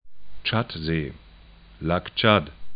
'tʃat-ze: